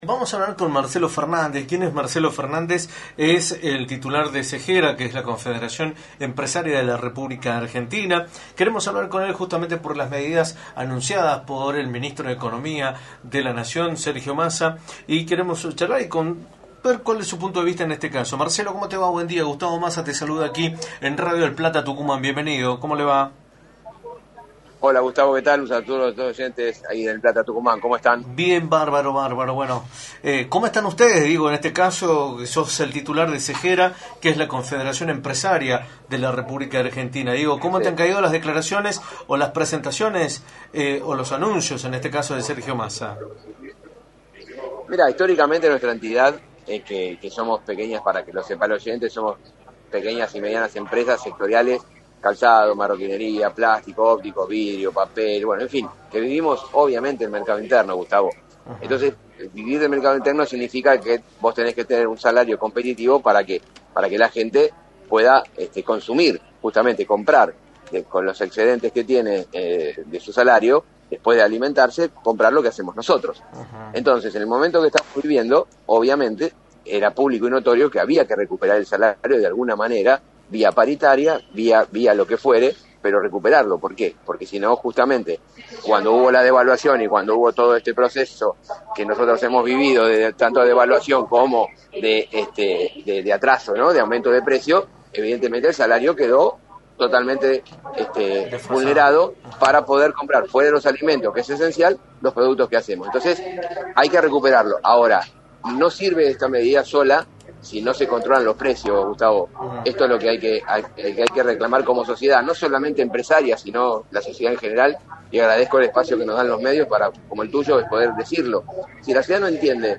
entrevista para “La Mañana del Plata”, por la 93.9.